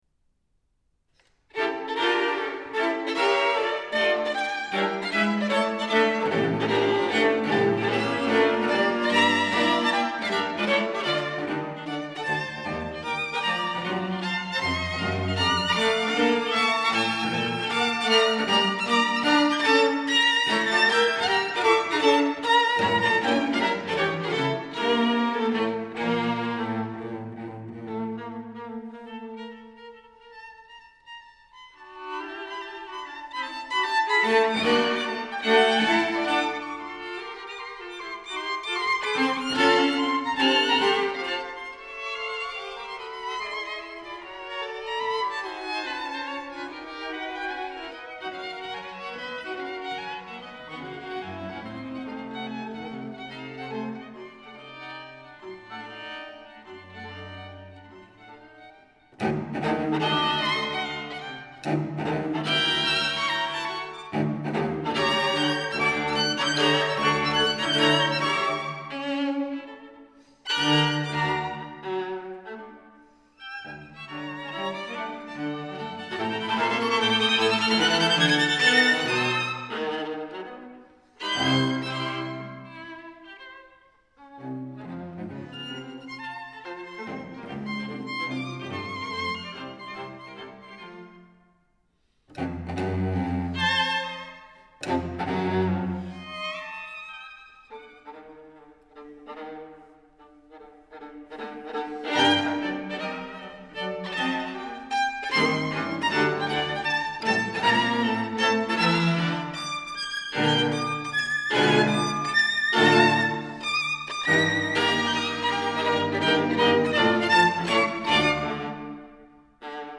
Oui, ce scherzo du quatuor � cordes en r� mineur est de hugo wolf (1860-1903)!
prose_WOLF_string_quartett_scherzo.mp3